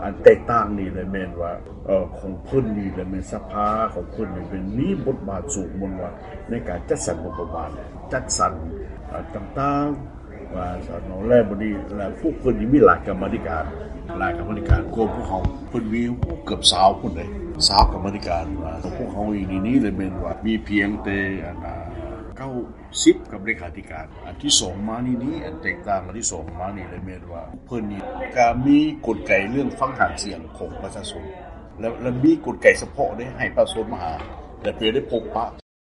ສຽງທ່ານສັນຍາ ປຣະເສີດ, ປະທານກຳມາທິການການພົວພັນຕ່າງປະເທດຂອງສະພາແຫ່ງຊາດລາວ